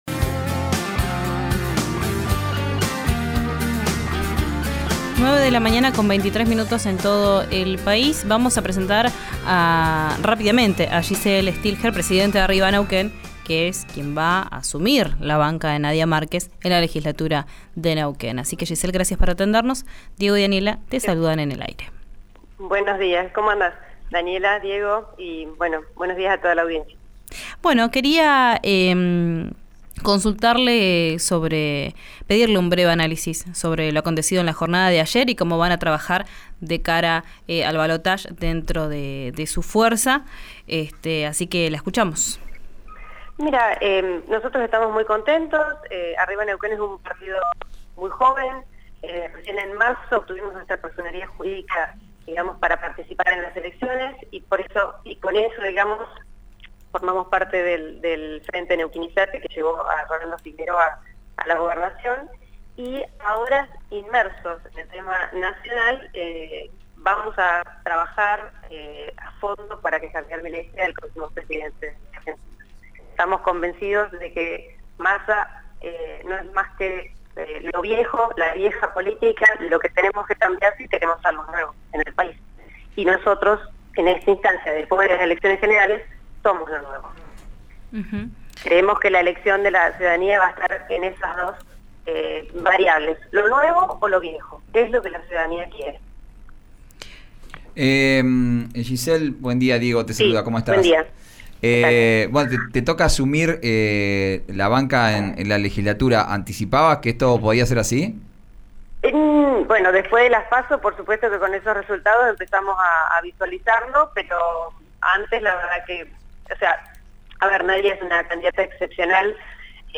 En diálogo con RÍO NEGRO RADIO, Stillger confirmó que asumirá la banca en diciembre y que será la primera vez que tenga un cargo de funcionaria pública, porque siempre trabajó en la actividad privada.